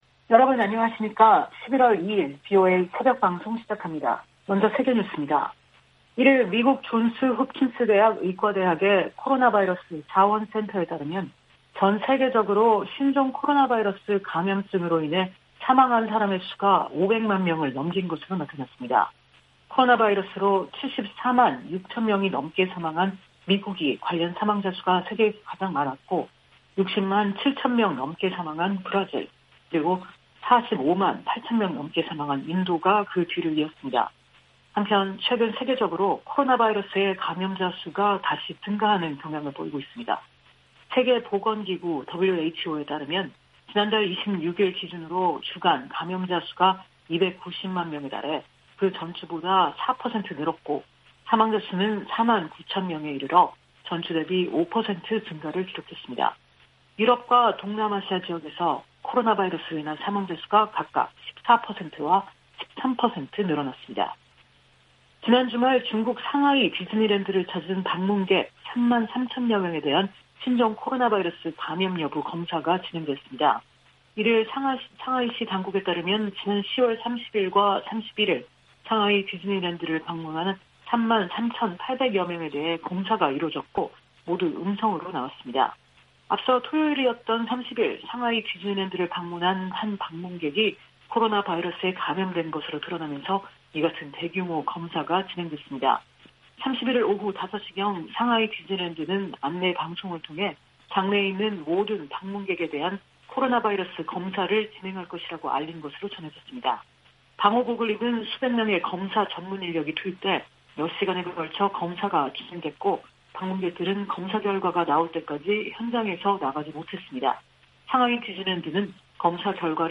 세계 뉴스와 함께 미국의 모든 것을 소개하는 '생방송 여기는 워싱턴입니다', 2021년 11월 2일 아침 방송입니다. '지구촌 오늘'에서는 주요 20개국(G20) 정상들이 기온 상승 섭씨 1.5도 이내 제한에 합의한 소식, '아메리카 나우'에서는 젠 사키 백악관 대변인 확진 판정을 비롯한 코로나 사태 관련 이야기 전해드립니다.